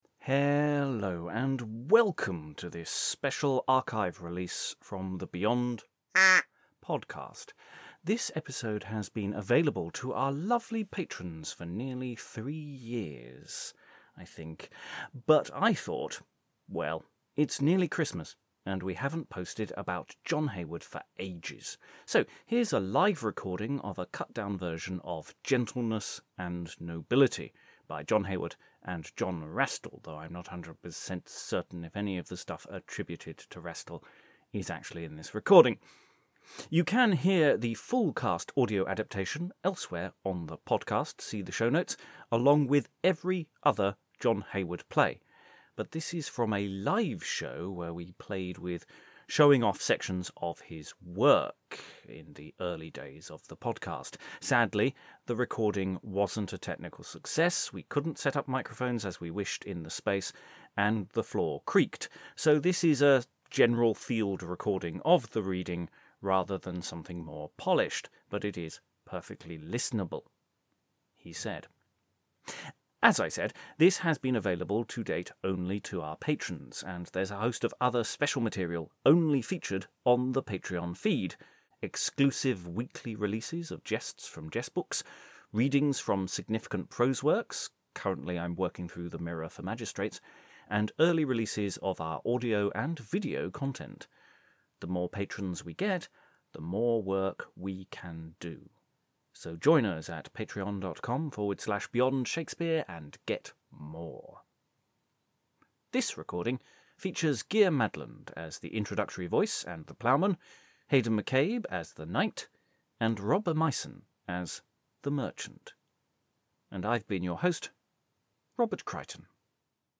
An archive recording of a Live reading of a cut down text of Gentleness and Nobility.
A reading of an edited version of Gentleness and Nobility by John Heywood and John Rastell, performed before a live audience in 2018. A Knight overhears a Merchant boasting of his nobleness, sparking an argument between the two as to what is a gentleman.